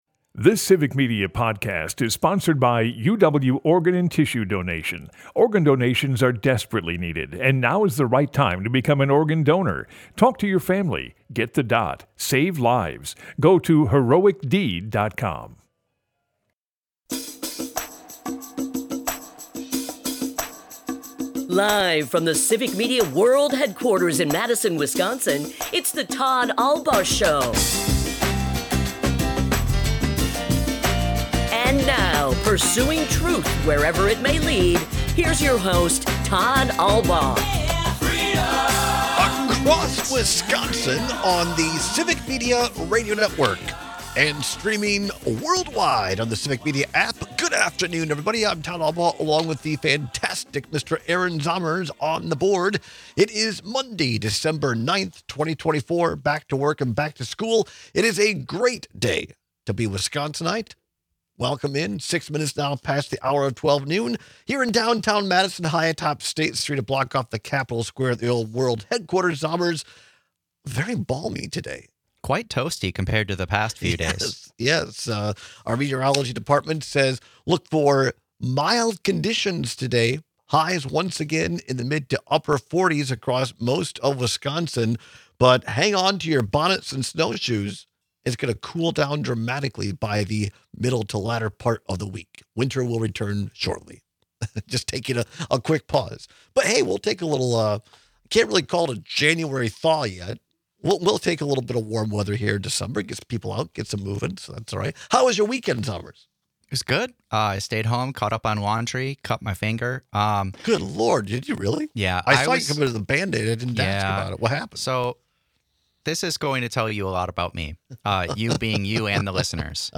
We listen to a portion of the interview, in which Trump is preemptively blaming the media for anticipated backlash to his mass deportation plan. As we’ve said before, mass deportation of undocumented people would destroy the agricultural economy both in Wisconsin and nationwide.